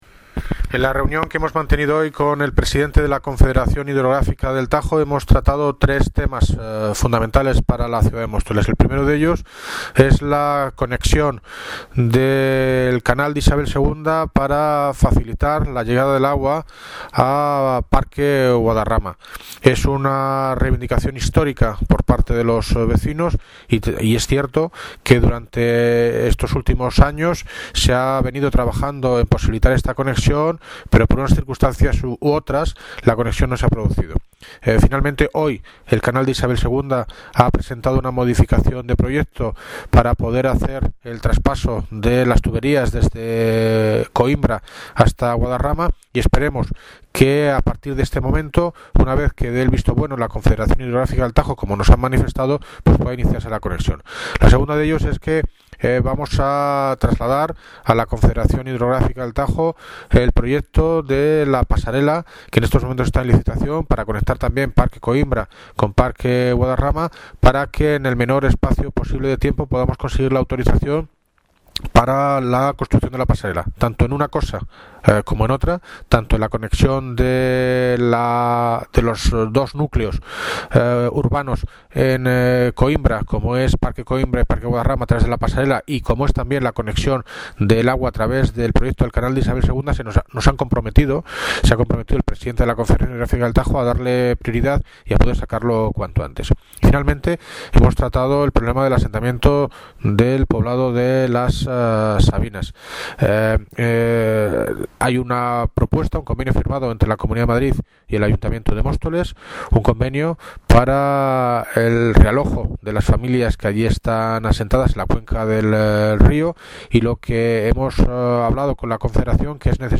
Audio - David Lucas (Alcalde de Móstoles) Sobre Confederación Hidrográfica del Tajo